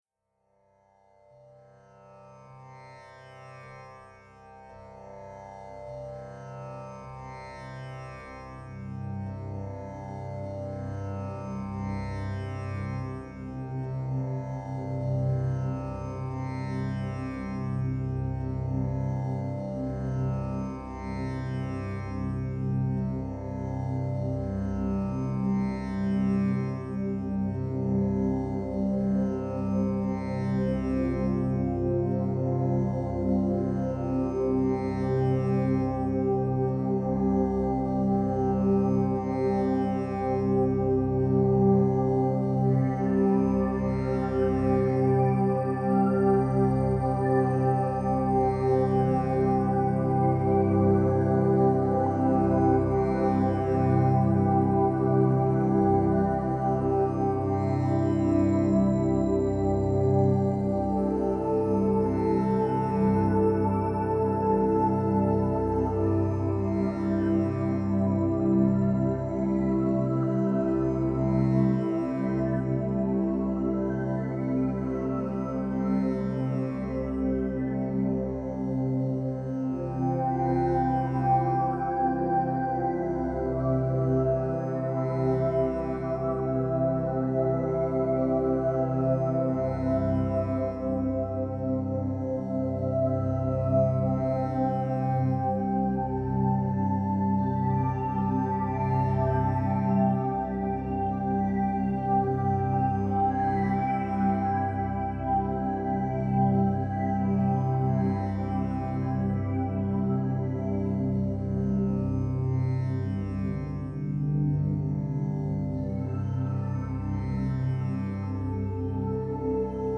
MeditationSoundscapeDeepAlpha
meditationsoundscapedeepalpha.mp3